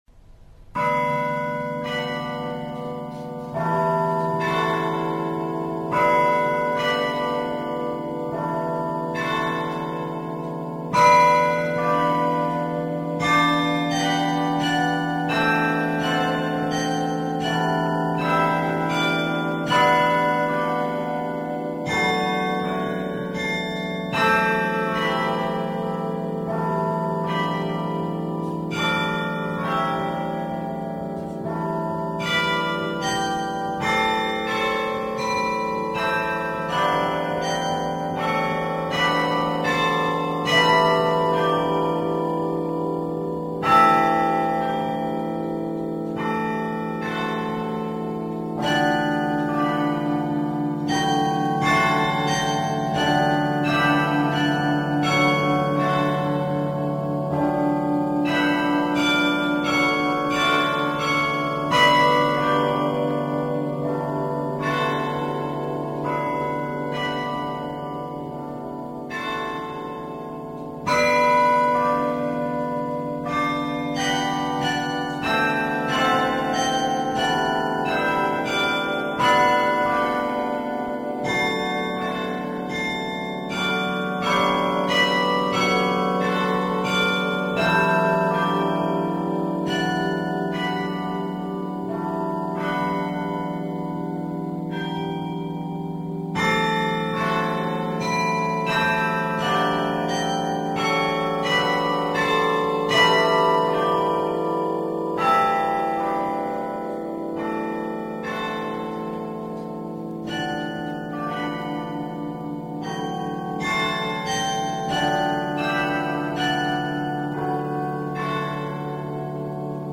Klockspelet i S:ta Clara kyrka
Klockspelet
Det har 35 bronsklockor med kromatiskt omfång av tre oktaver.
Den största klockan väger 1.700 kg, och den minsta 20 kg.